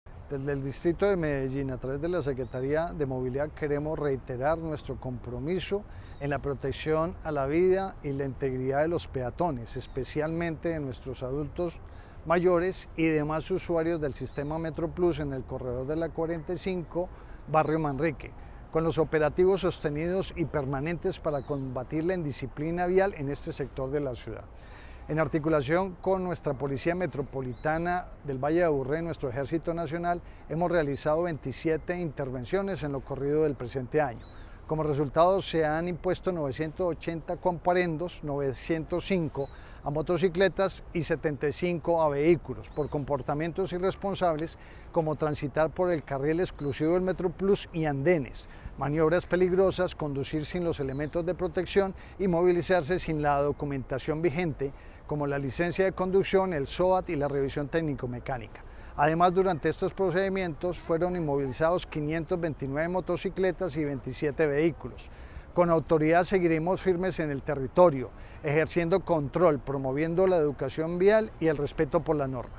Declaraciones secretario de Movilidad, Pablo Ruiz
Declaraciones-secretario-de-Movilidad-Pablo-Ruiz-3.mp3